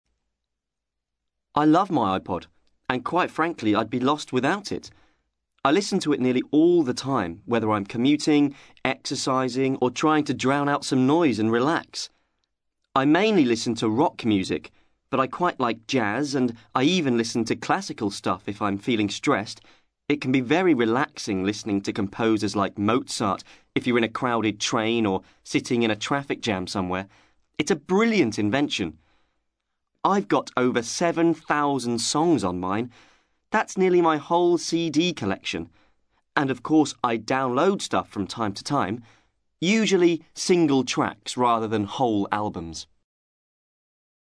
ACTIVITY 183: You will hear five short extracts in which five people are talking about an aspect of music which is or has been important to them.